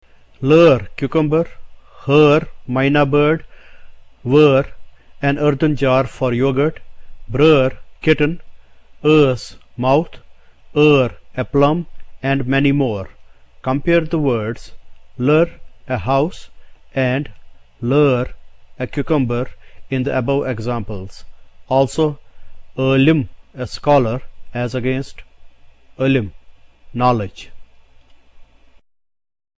The fourth vowel also has an umlaut placed over the symbol A. It has one more (.) over the symbol for the third vowel and indicates an "extension" of that sound.
This vowel has the same sound as that of the letters "ER" in the English word PERT.